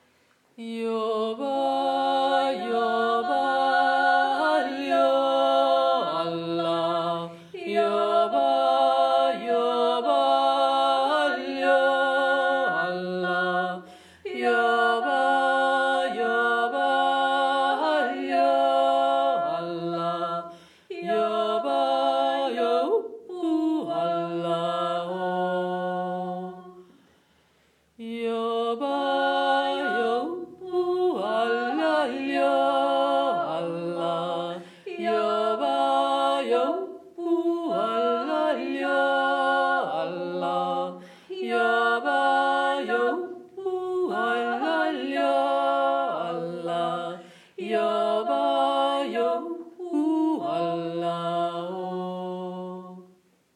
Die Kolumne FRAU IM FOKUS wird hiermit durch das Gespräch mit einer Jodlerin und Jodellehrerin eröffnet.
eingenistet in die Musik eines irischen Klavier-Virtuosen und eines arabischen Multi-Percussionisten
Trio